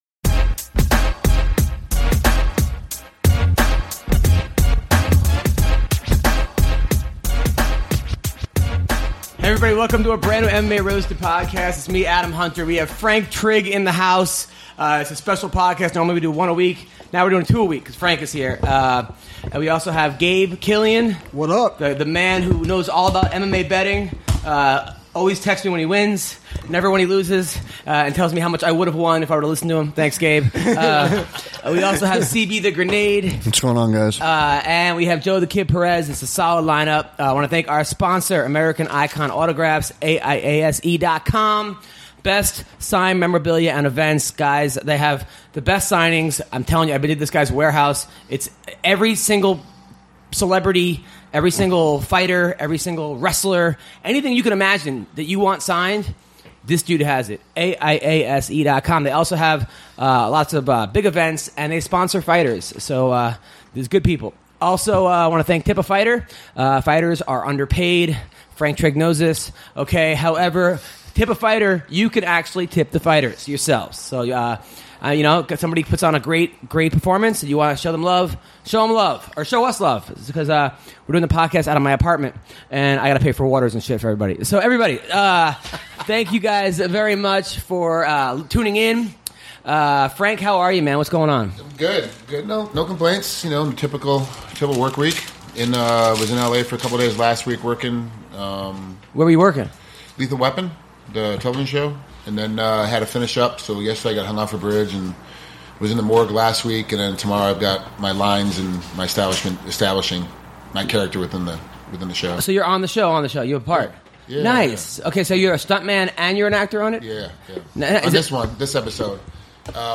UFC fighter Rashad Evans calls in to break down his upcoming fight against Dan Kelly.